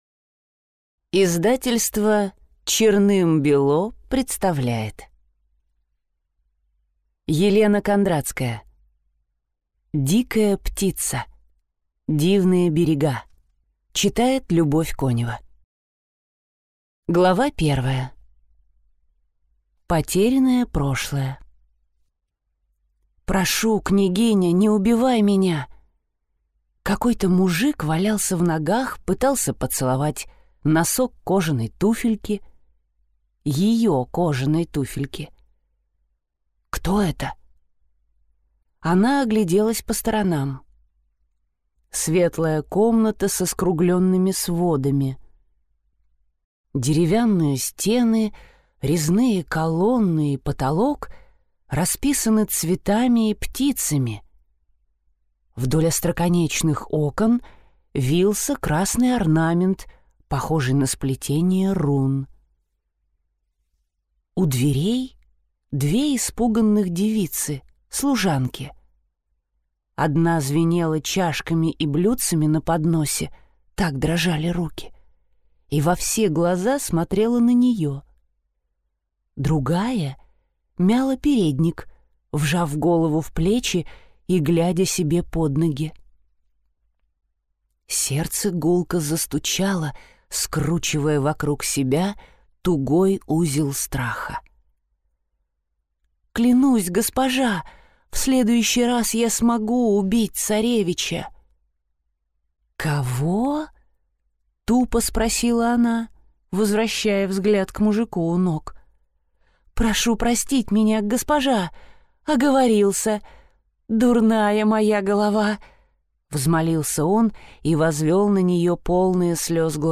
Дикая птица (слушать аудиокнигу бесплатно) - автор Елена Кондрацкая